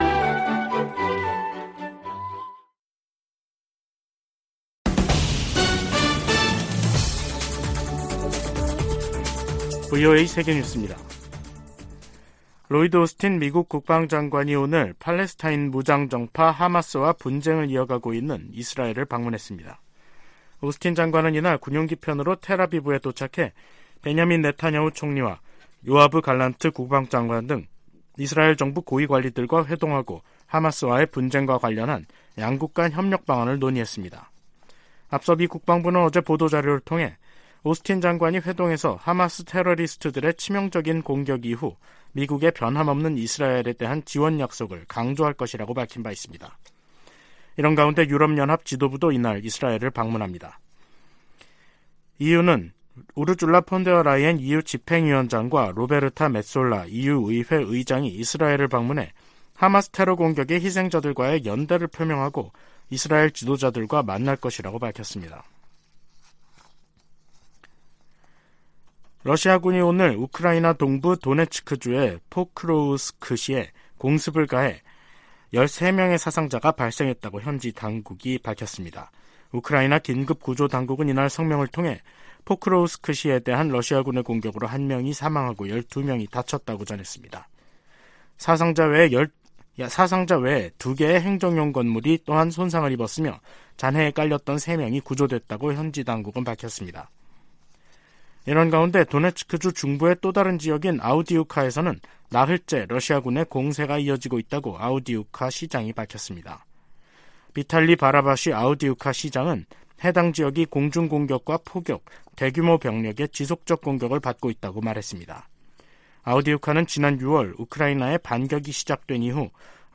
VOA 한국어 간판 뉴스 프로그램 '뉴스 투데이', 2023년 10월 13일 2부 방송입니다. 이스라엘과 하마스의 전쟁 등 중동발 위기가 한반도에 대한 미국의 안보 보장에 영향을 주지 않을 것이라고 백악관이 밝혔습니다. 북한이 하마스처럼 한국을 겨냥해 기습공격을 감행하면 한미연합사령부가 즉각 전면 반격에 나설 것이라고 미국 전문가들이 전망했습니다. 중국 내 많은 북한 주민이 송환된 것으로 보인다고 한국 정부가 밝혔습니다.